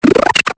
Cri de Poichigeon dans Pokémon Épée et Bouclier.